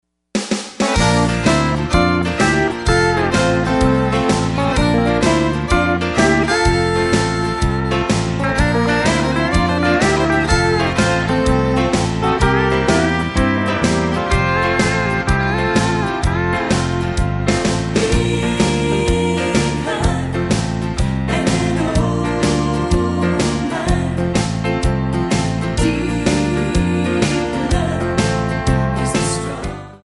MPEG 1 Layer 3 (Stereo)
Backing track Karaoke
Country, 1990s